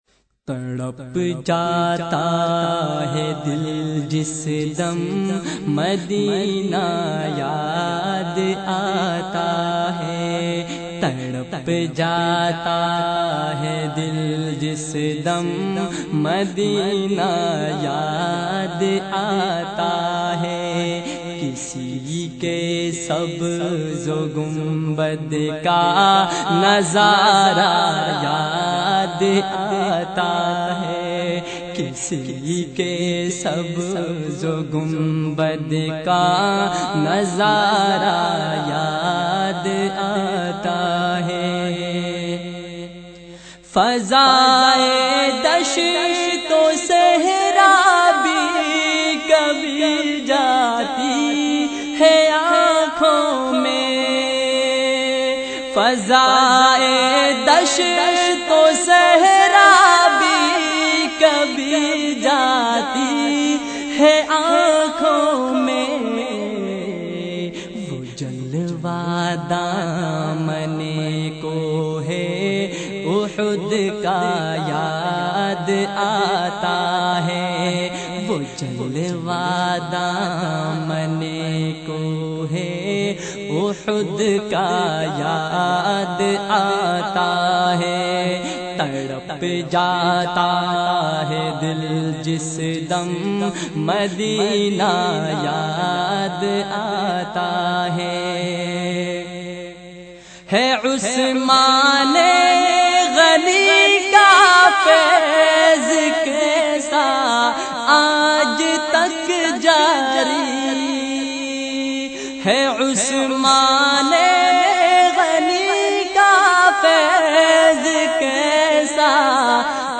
in a Heart-Touching Voice
performs a naat with emotional engagement